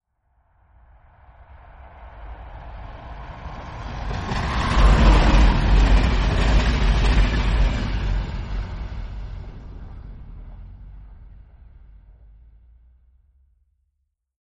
train_passes.mp3